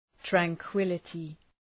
Προφορά
{træŋ’kwılətı}